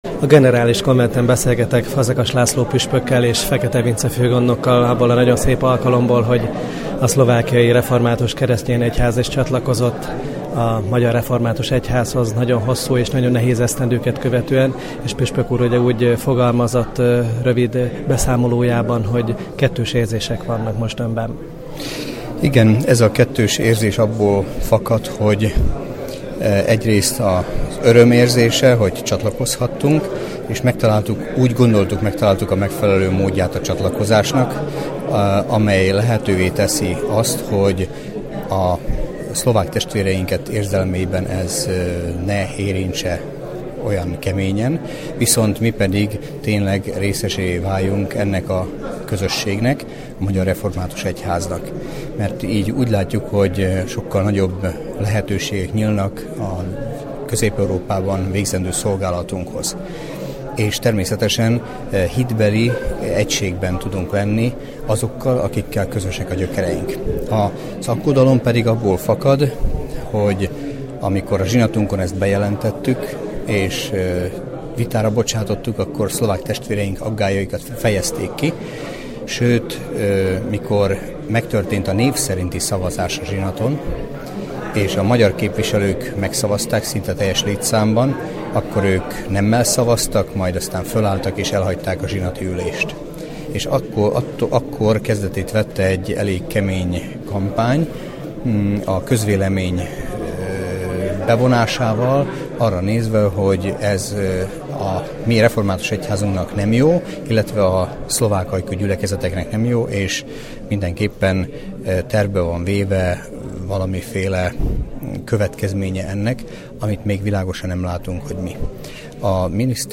Fényképeket és rádiós riportokat nézhetnek meg illetve hallgathatnak meg a Generális Konvent június 21-22-ei plenáris üléséről.